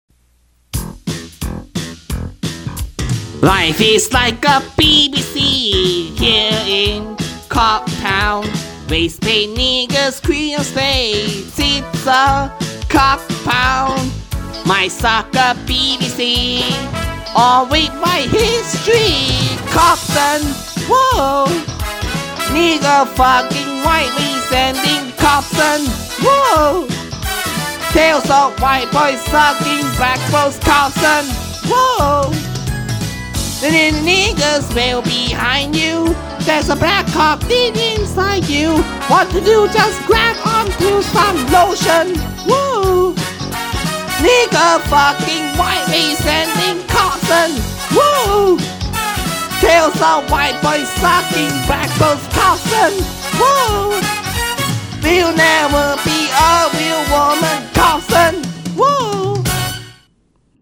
Cobson Theme Song (Mono Mix).mp3 📥 (746.61 KB)